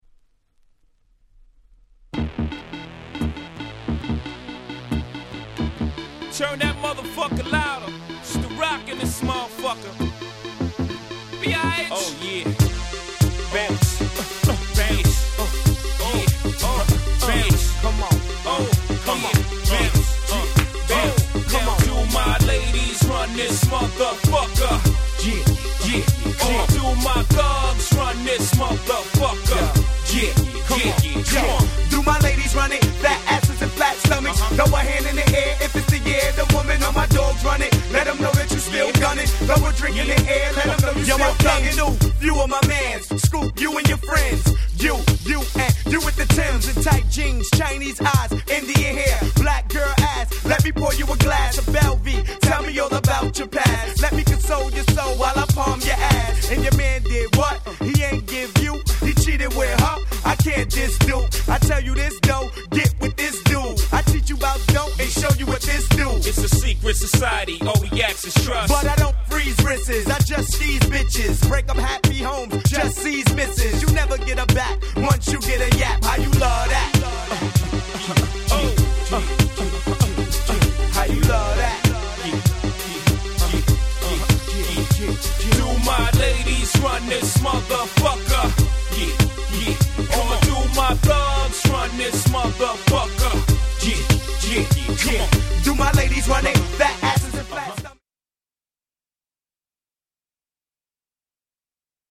01' Big Hit Hip Hop !!
当時からフロア爆発の1曲。